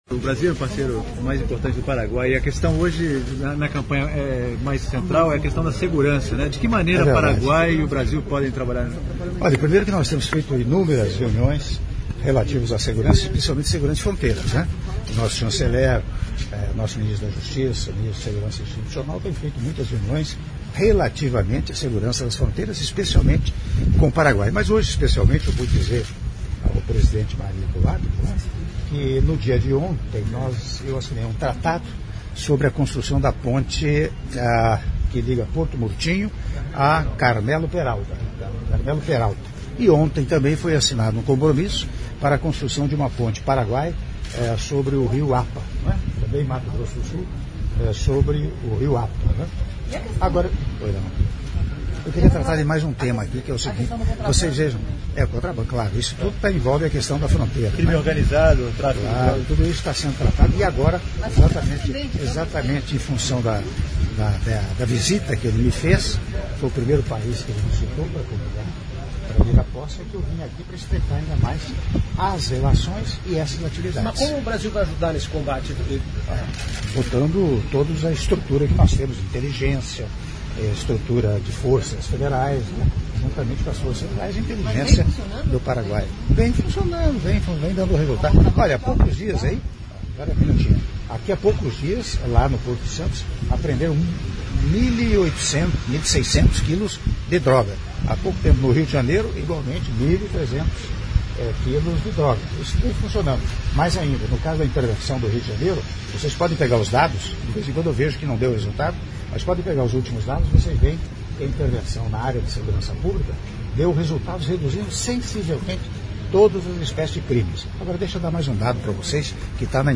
Áudio da entrevista coletiva concedida pelo Presidente da República, Michel Temer, após Te Deum solene pelo novo Período Constitucional e em Comemoração ao 481º Aniversário da Fundação da Cidade de Assunção- Assunção/Paraguai (04min08s)